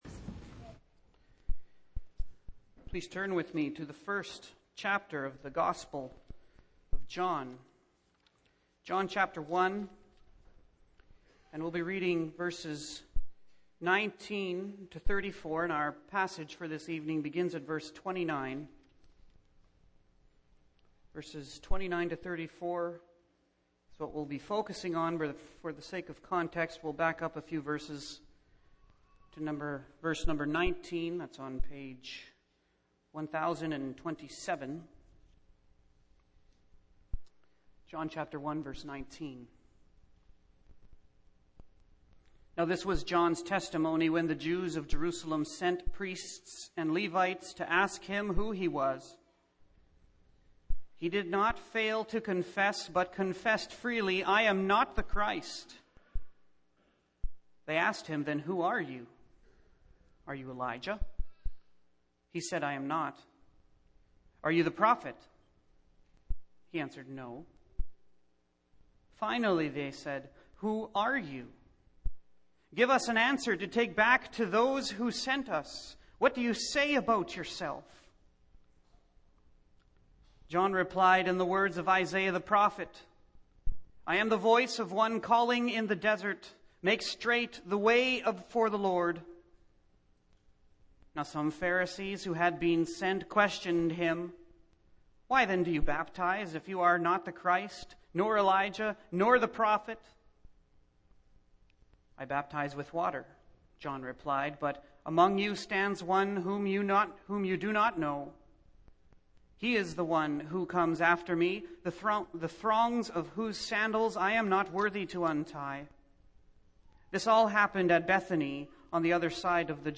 Series: Single Sermons
Service Type: Evening